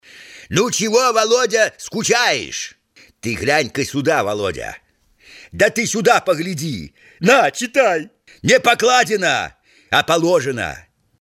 Пародия на голос персонажа Папанова из фильма “Бриллиантовая рука” - Лелика
Категория: пародии
Характеристика: Пародист